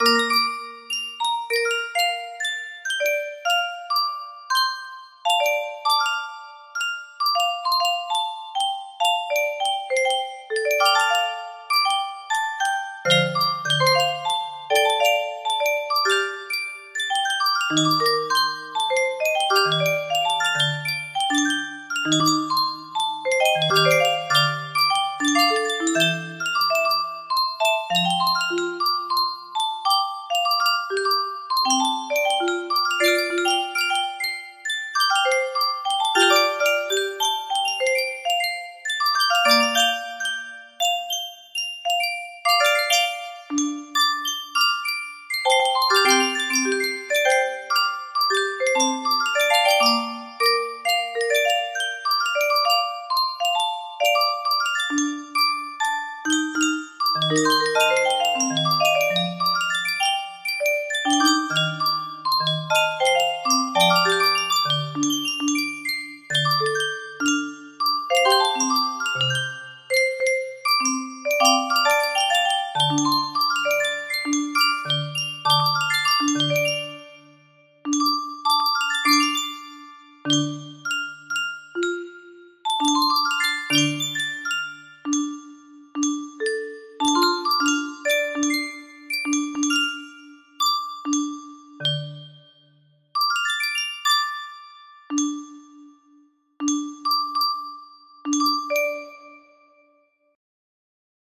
Threads Of Gold 6 music box melody
Full range 60